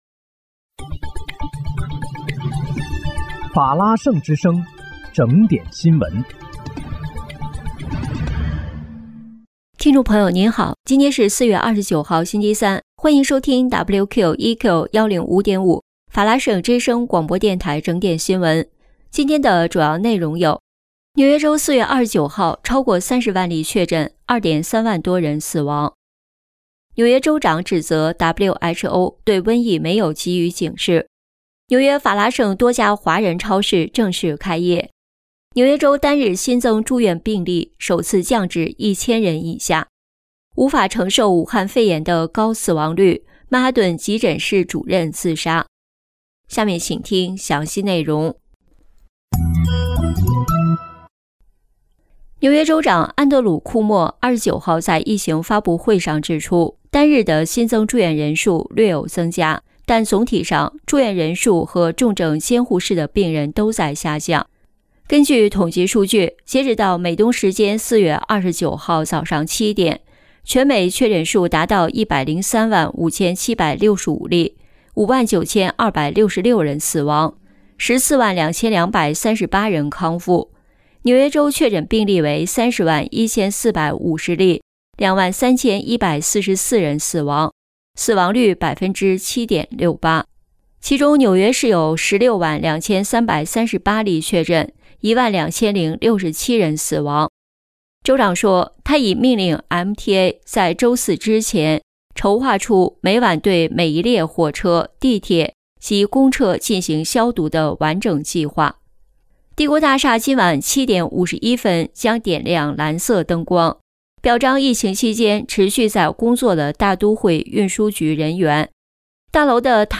4月29日（星期三）纽约整点新闻
听众朋友您好！今天是4月29号，星期三，欢迎收听WQEQ105.5法拉盛之声广播电台整点新闻。